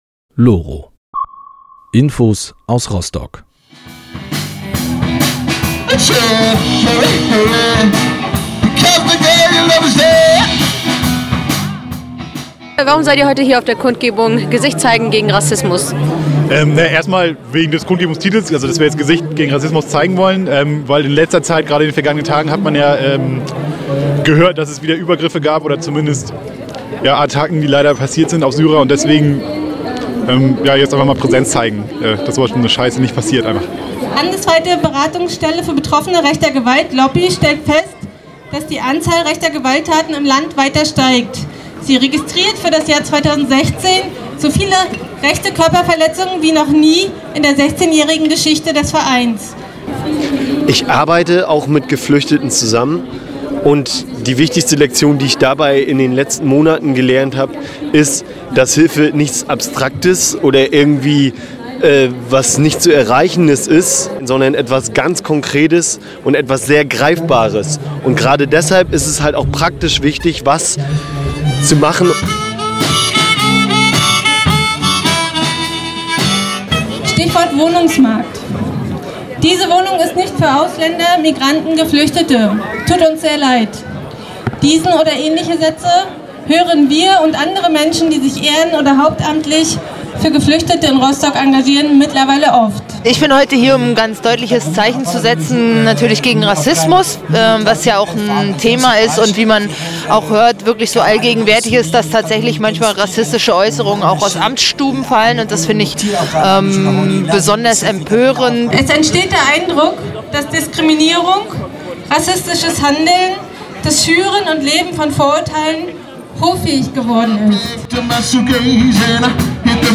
Deshalb gab es vergangenen Freitag eine Kundgebung am Universitätsplatz: Gesicht zeigen gegen Rassismus.